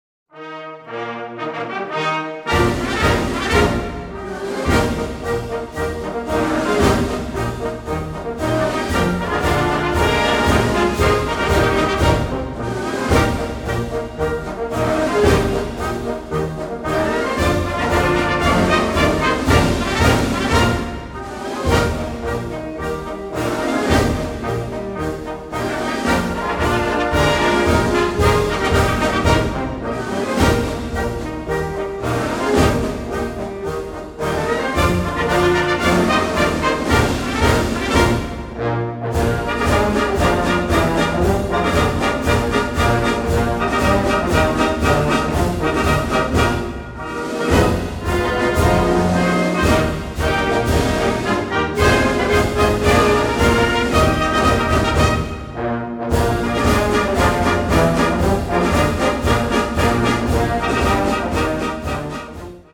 Kategorie Blasorchester/HaFaBra
Unterkategorie Strassenmarsch
Besetzung Ha (Blasorchester)